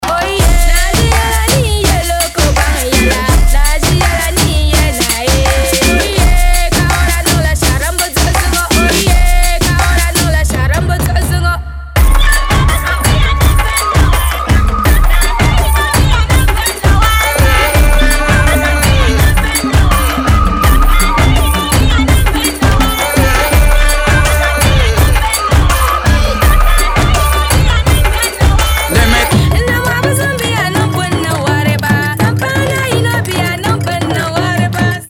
• Качество: 320, Stereo
Hiphop
латина
Reggaeton